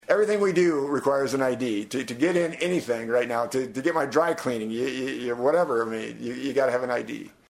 FEENSTRA MADE HIS COMMENTS DURING AN INTERVIEW AT KSCJ.